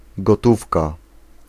Ääntäminen
Synonyymit monnaie liquide maille numéraire Ääntäminen Tuntematon aksentti: IPA: /ɛs.pɛs/ Haettu sana löytyi näillä lähdekielillä: ranska Käännös Ääninäyte 1. gotówka {f} Suku: f . Espèces on sanan espèce monikko.